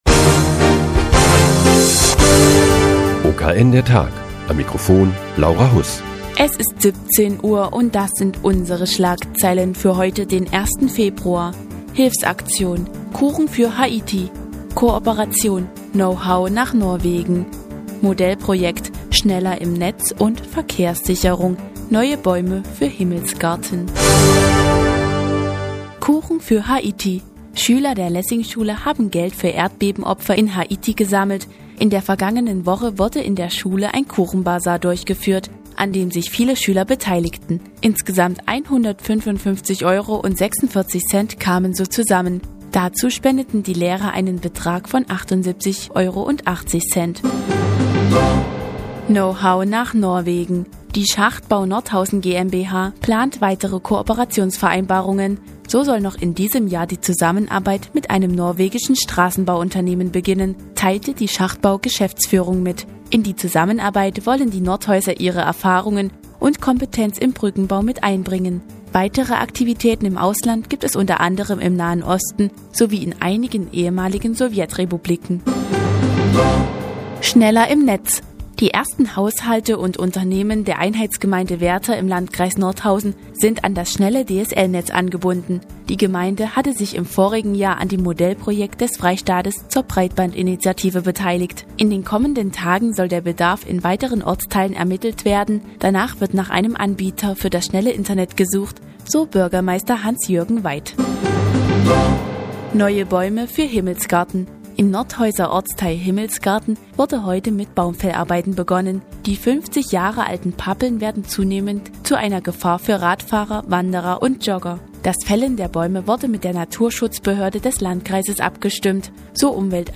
Die tägliche Nachrichtensendung des OKN ist nun auch in der nnz zu hören. Heute geht es um eine Hilfsaktion der Lessingschule für Haiti und die Kooperation der Schachtbau Nordhausen GmbH mit einem norwegischen Straßenbauunternehmen.